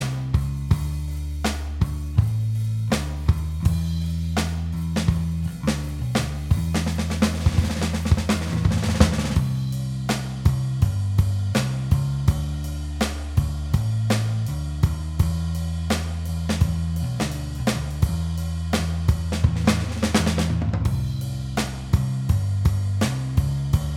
Shortened Version Blues 3:05 Buy £1.50